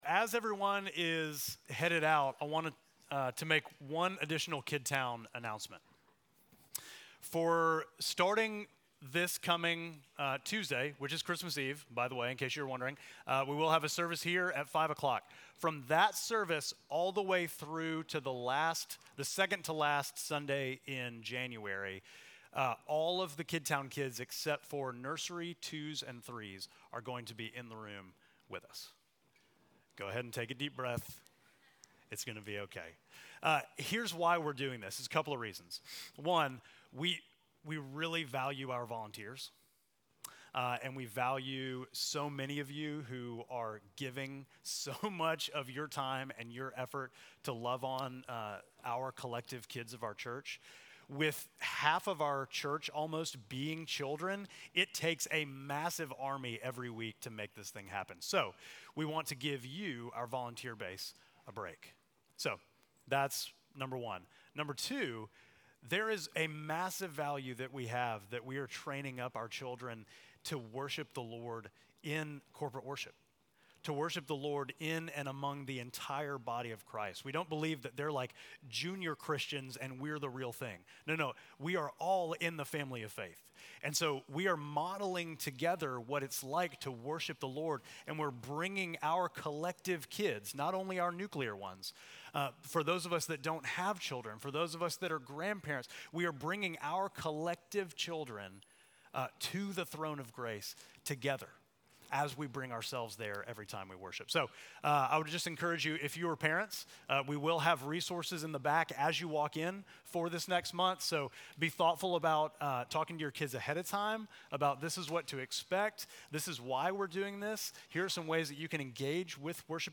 Midtown Fellowship Crieve Hall Sermons Joy in the Promised Land Dec 22 2024 | 00:42:23 1x 00:00 / 00:42:23 Subscribe Share Apple Podcasts Spotify Overcast RSS Feed Share Link Embed